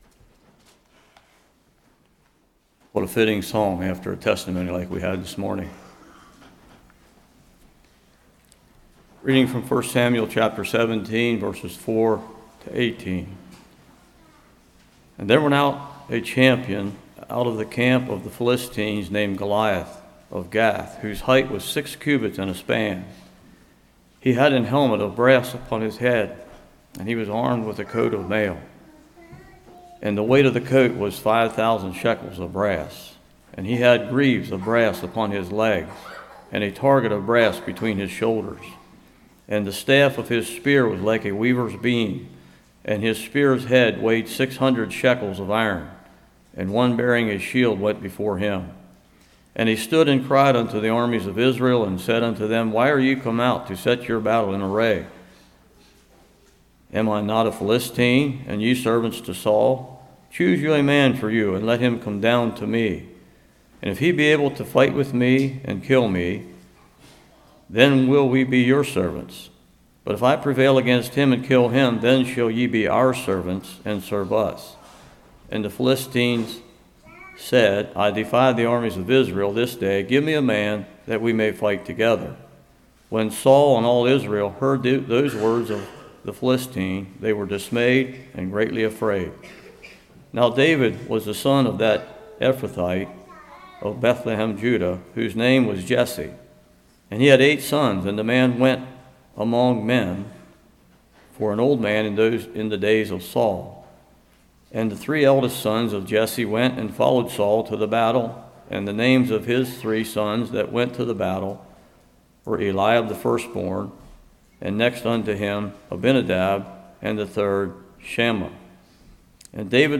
1 Samuel 17;1-50 Service Type: Morning Giants in our life can cause confusion,dismay